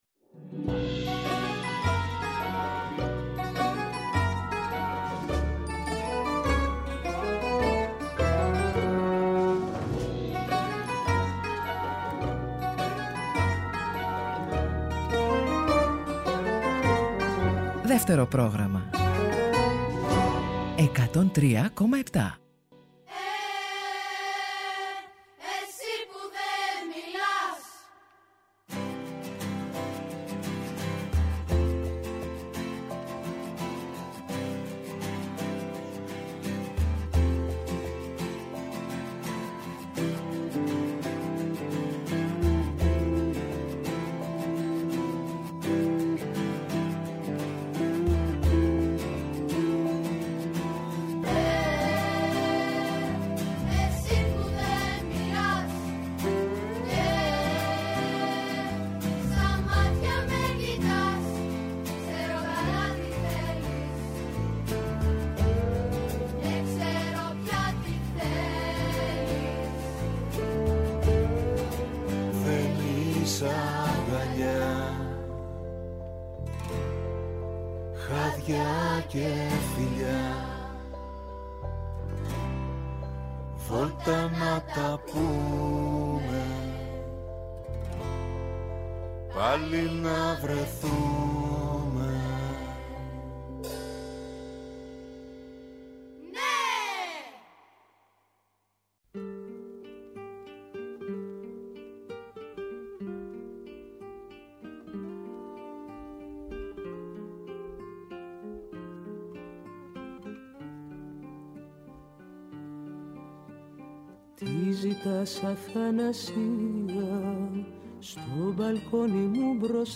”Ο Δημήτρης και η Δήμητρα” έχουν την τιμητική τους στα τραγούδια του ”Εσύ που δεν μιλάς”.